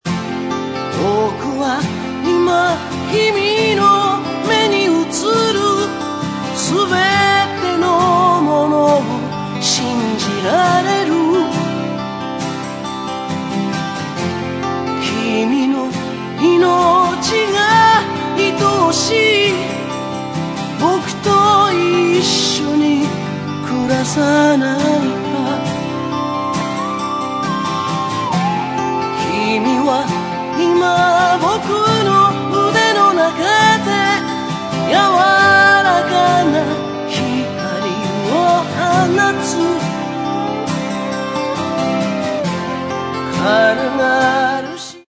オリジナルアルバム
アコースティックな音色が胸にやさしく響いてくる味わいがたまりません。